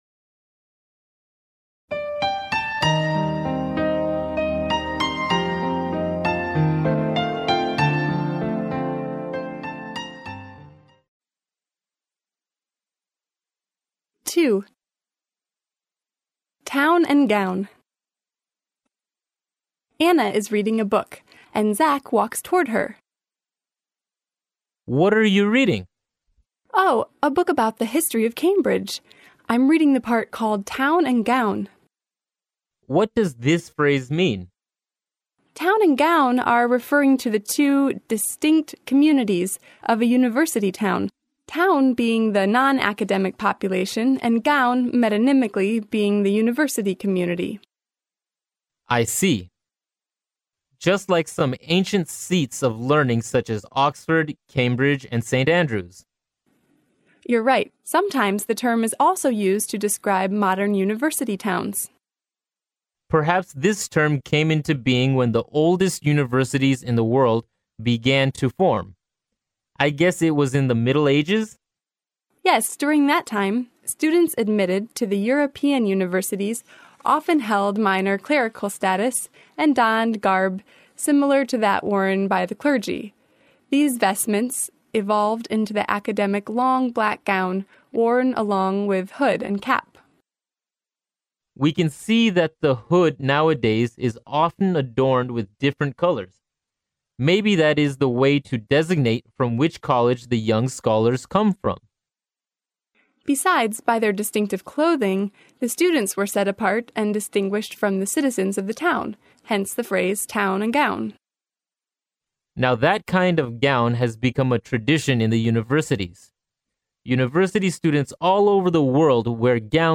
剑桥大学校园英语情景对话02：城市与大学（mp3+中英）